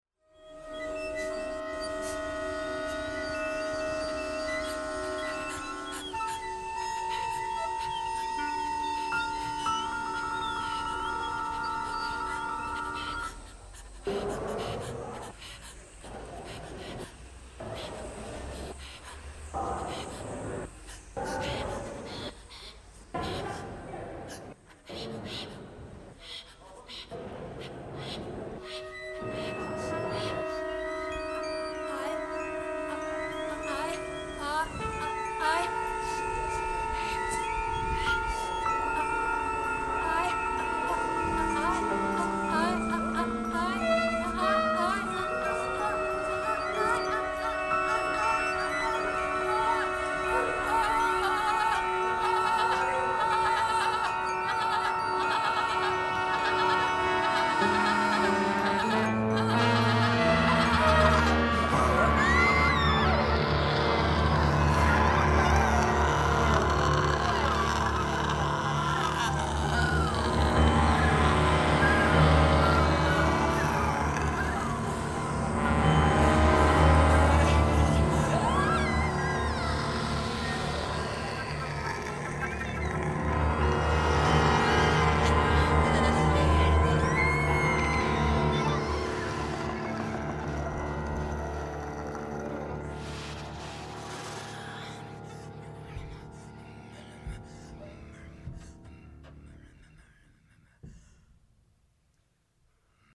eine Suite in 3 Sätzen